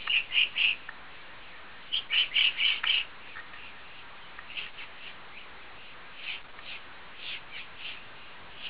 Virtual tour of Petaluma's Lafferty Ranch in autumn
woodpckr.aiff